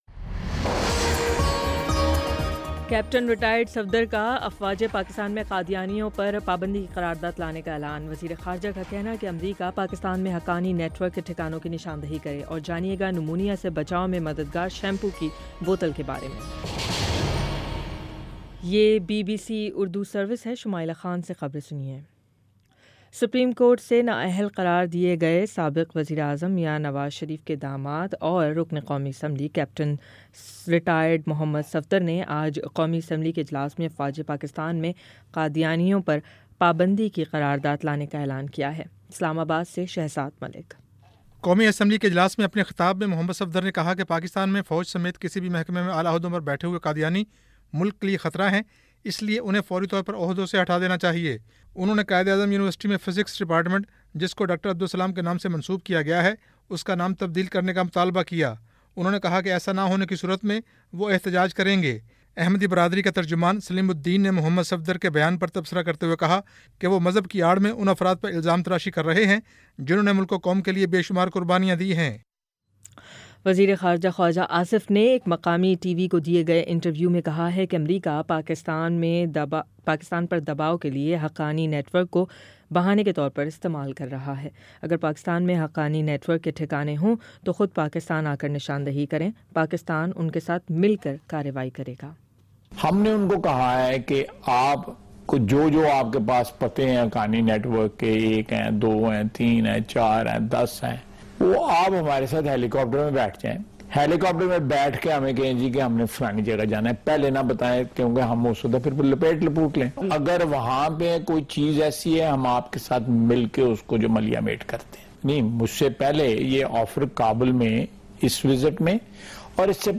اکتوبر 10 : شام پانچ بجے کا نیوز بُلیٹن